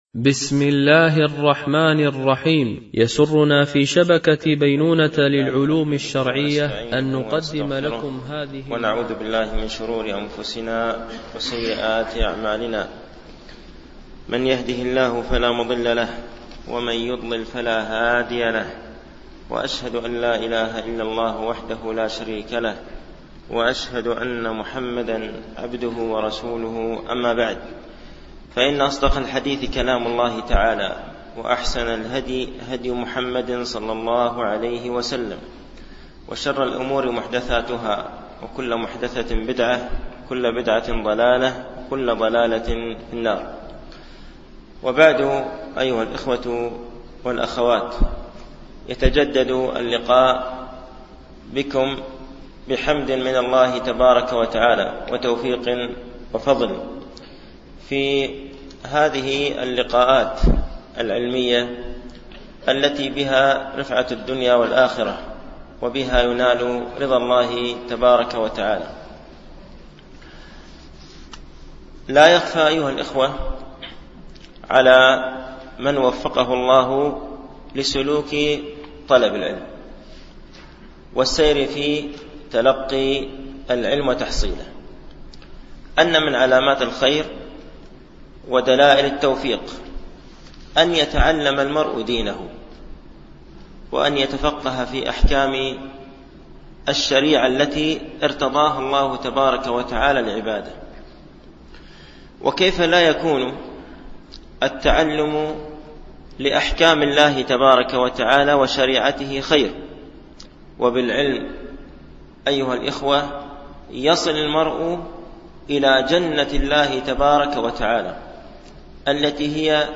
شرح كتاب سؤال وجواب في أهم المهمات ـ الدرس الثامن